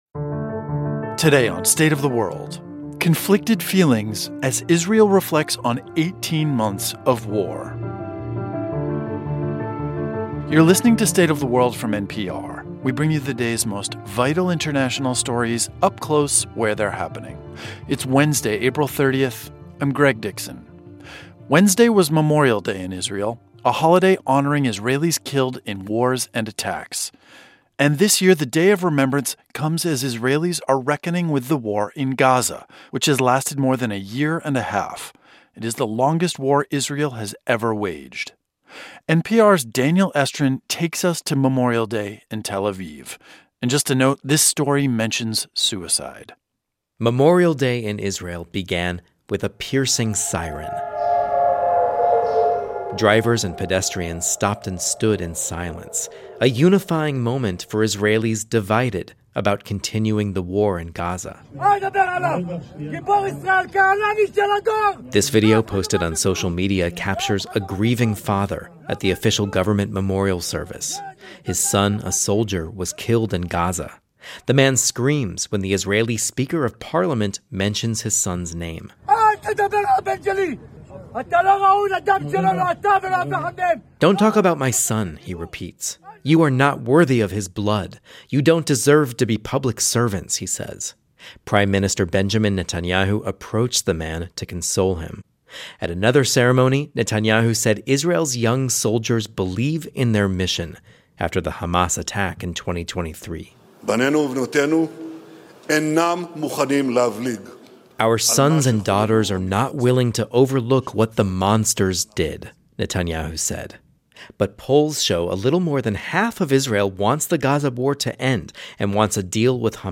This year it comes as they are reckoning with an ongoing war that is already the longest war the country has ever waged. We go to Tel Aviv to see what this year's Memorial Day in Israel is like.